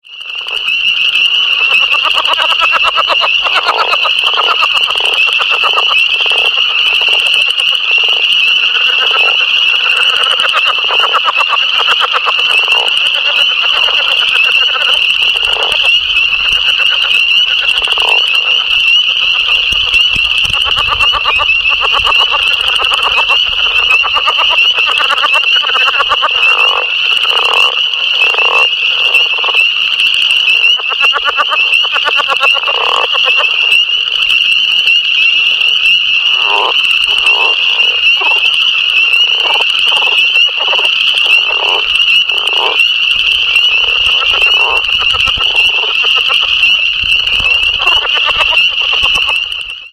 Coastal Plains Leopard Frog (Lithobates sphenocephalus utricularius)